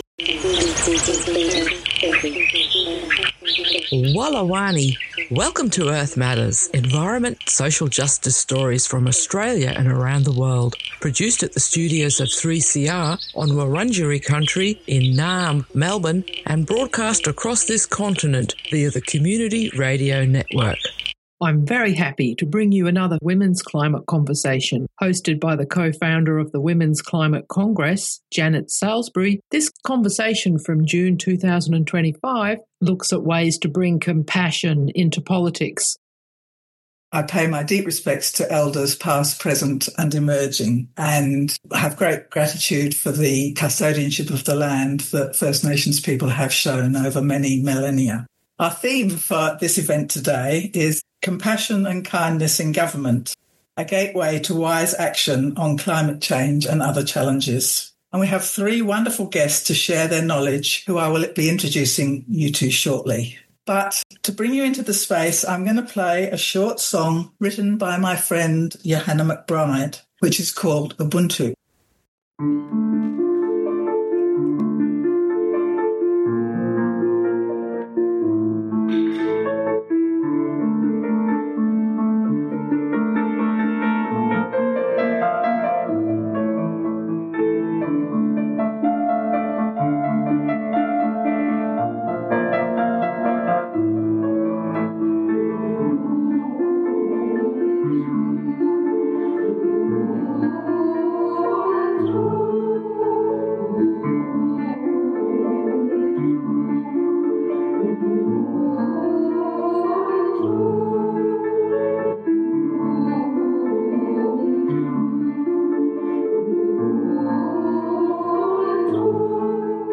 Meet our conversationalists